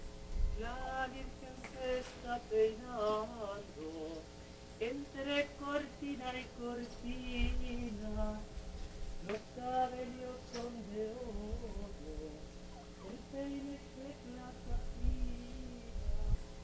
Source: Monk singing
Monk4.aif